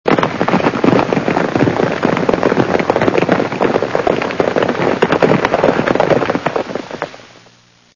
trampling.ogg